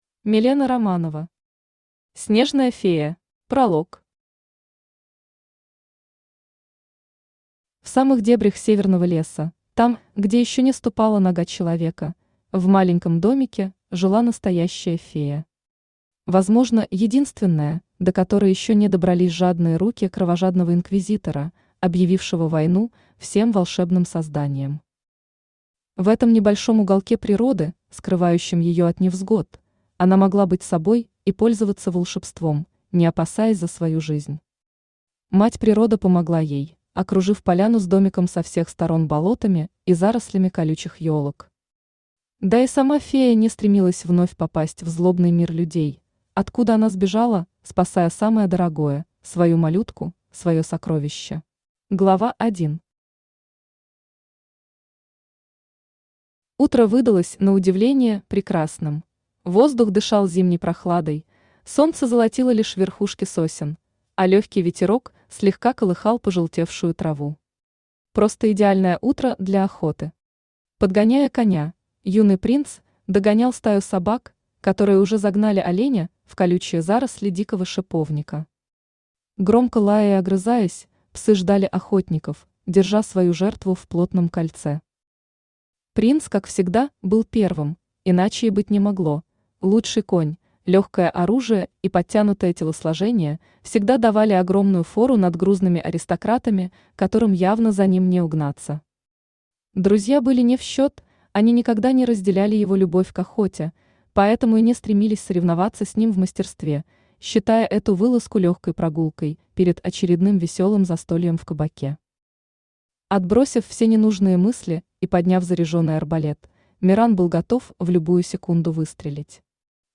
Аудиокнига Снежная фея | Библиотека аудиокниг
Aудиокнига Снежная фея Автор Милена Романова Читает аудиокнигу Авточтец ЛитРес.